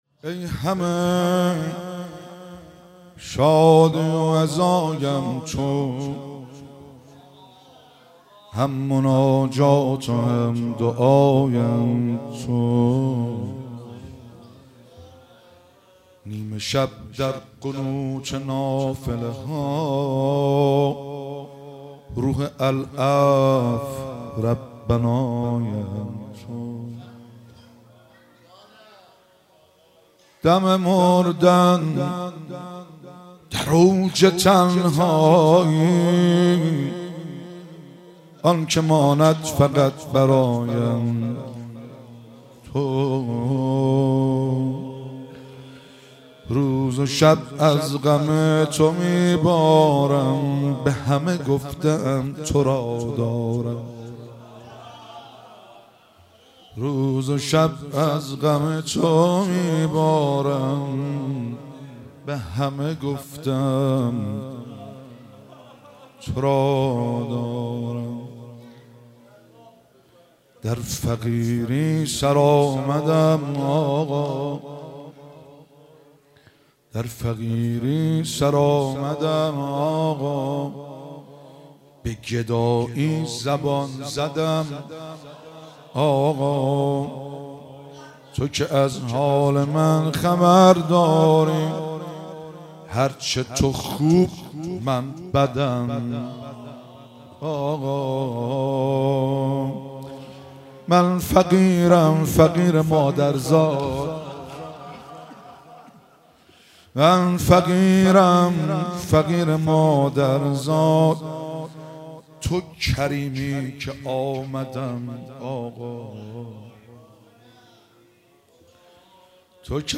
مراسم مناجات خوانی شب دهم و عزاداری شب وفات حضرت خدیجه کبری سلام الله علیها ماه رمضان 1444
روضه- ای همه شادی و عزایم تو هم مناجات
مناجات- قلب مرا نرم کرد دیده ی بارانی ام